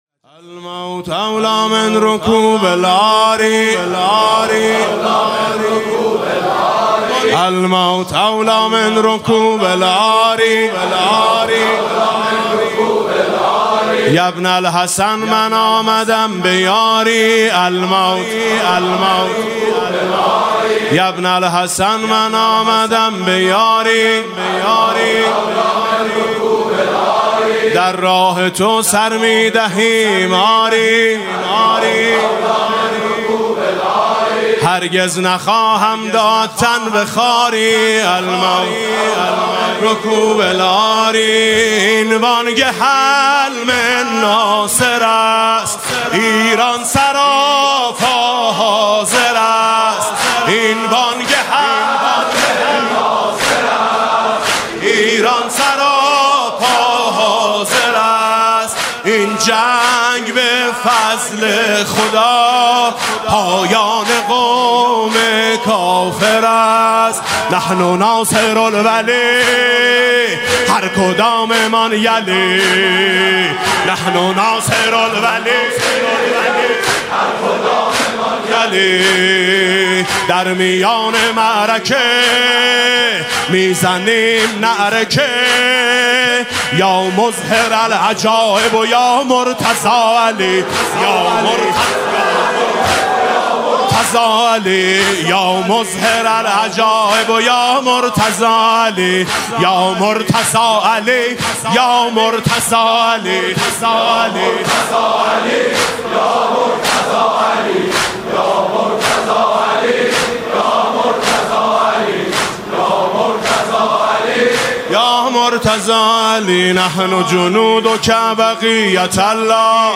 (رجز)